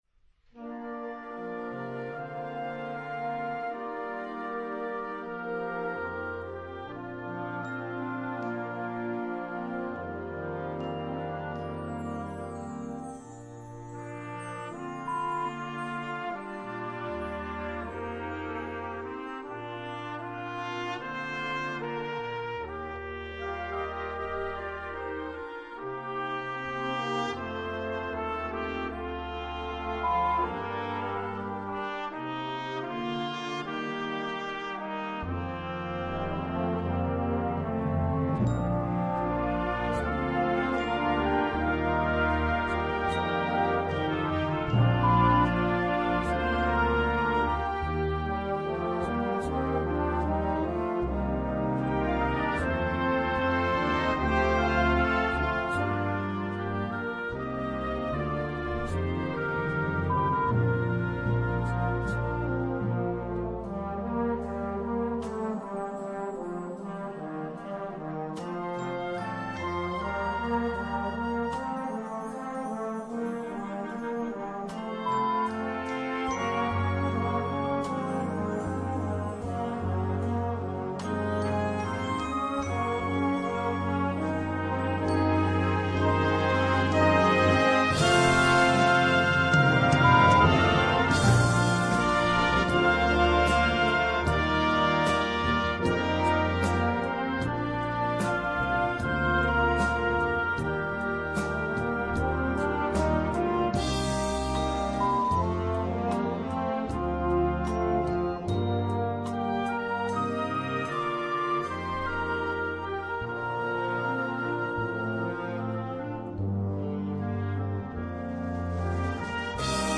Gattung: Filmmusik
Besetzung: Blasorchester
Diese eingängige Ballade